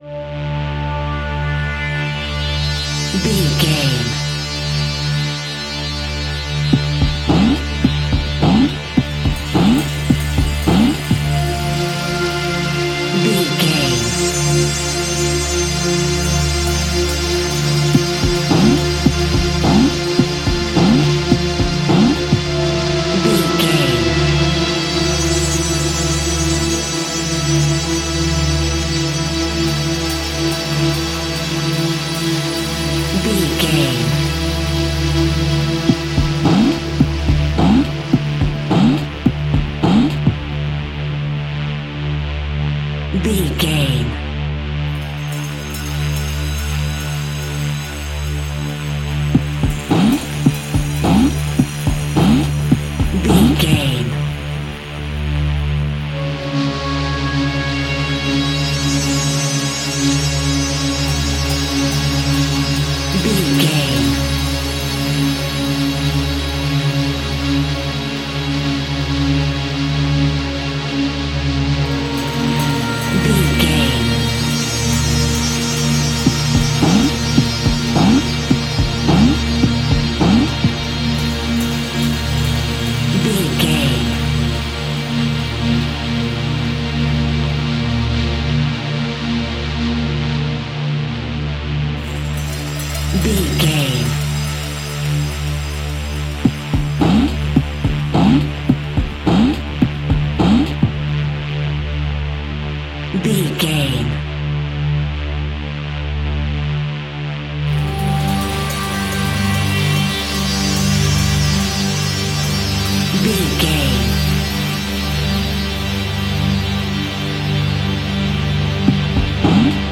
Aeolian/Minor
D
scary
tension
ominous
dark
suspense
haunting
eerie
synthesiser
horror
creepy
keyboards
ambience
pads
eletronic